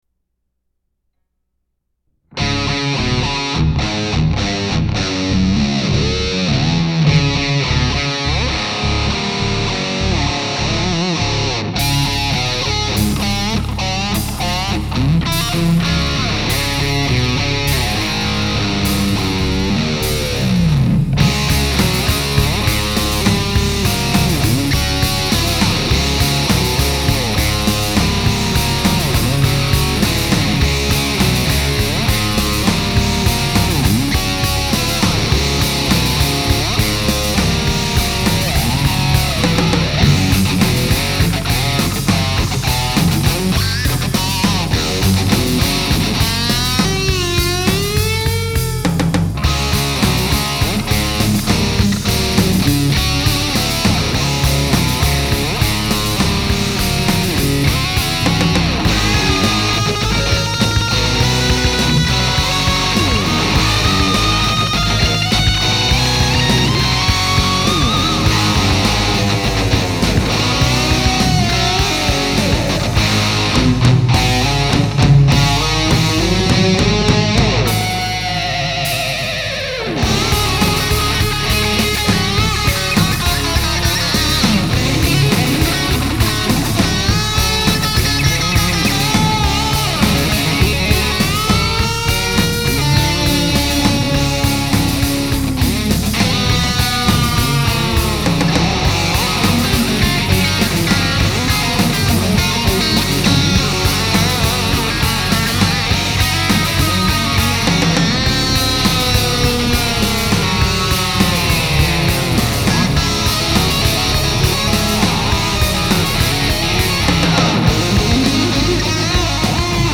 Instrumental for instructional purposes only
bass